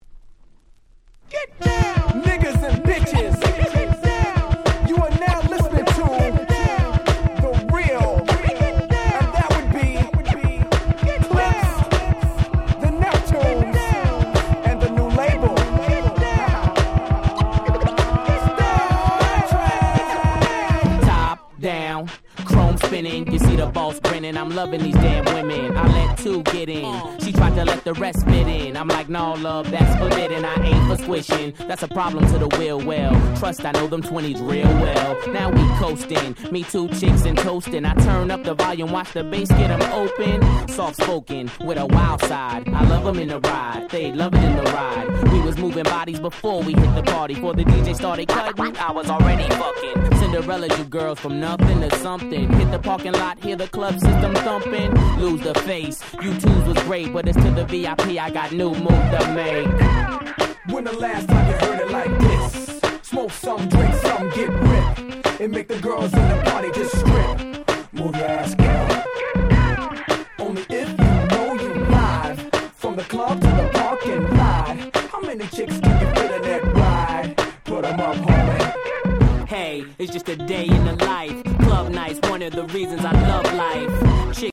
02' Super Hit Hip Hop !!